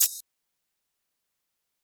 Closed Hats
Metro Hats [Shake].wav